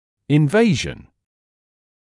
[ɪn’veɪʒn][ин’вэйжн]инвазия; начало заболевания, приступ болезни